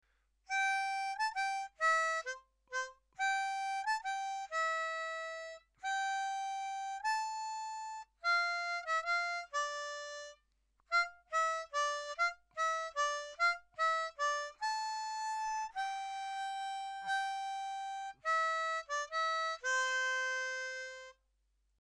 It’s a Sea Shanty sung by Sailors and Pirates. It only uses three holes but there is one jump to learn if you want the song to sound right.
Blow-The-Man-Down-full-version.mp3